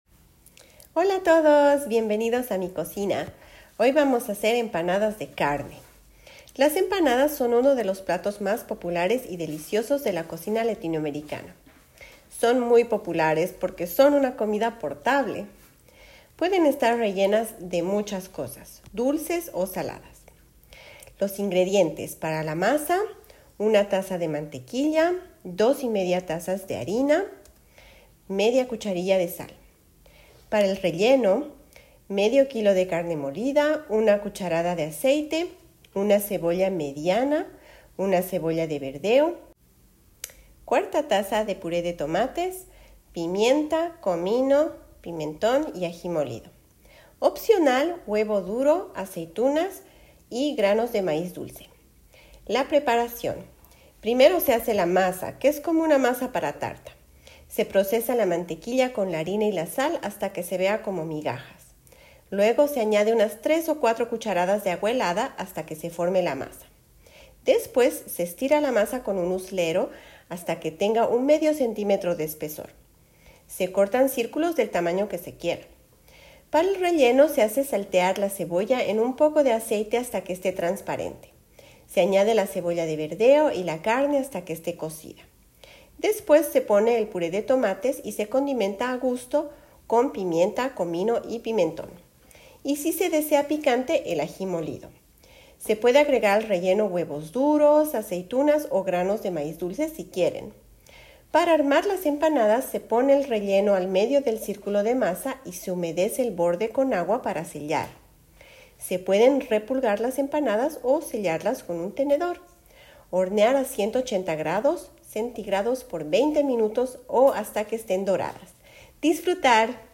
1- Viernes- Texte audio: Una receta para hacer empanadas de carne